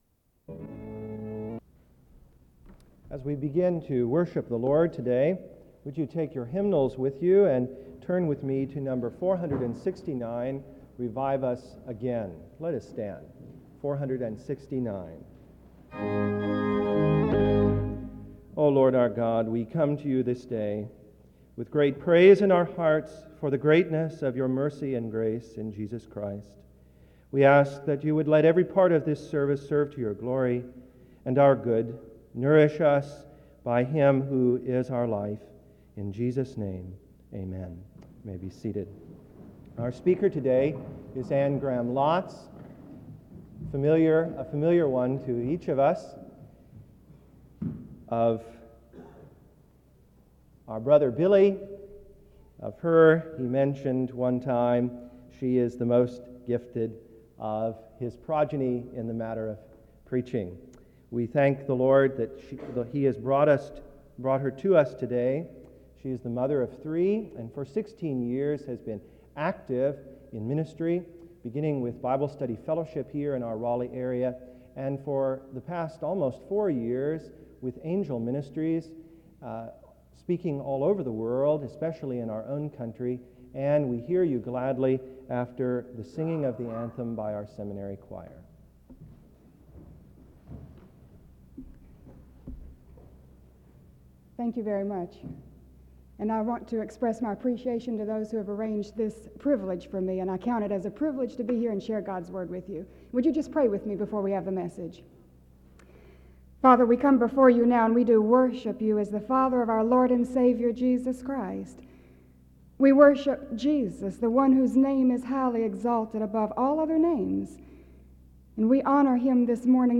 SEBTS Chapel - Anne Graham Lotz April 1, 1992
In Collection: SEBTS Chapel and Special Event Recordings SEBTS Chapel and Special Event Recordings - 1990s Miniaturansicht Titel Hochladedatum Sichtbarkeit Aktionen SEBTS_Chapel_Anne_Graham_Lotz_1992-04-01.wav 2026-02-12 Herunterladen